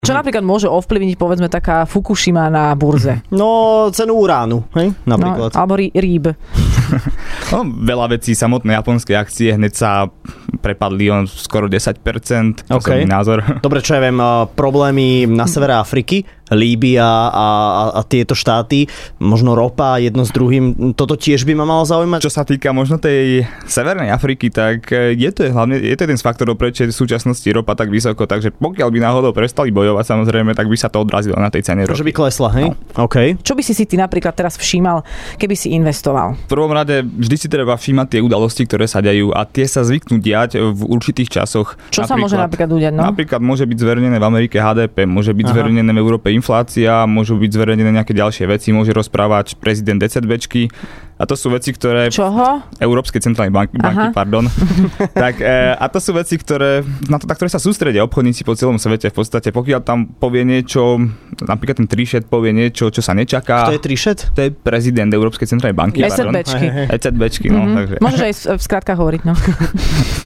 Ranná šou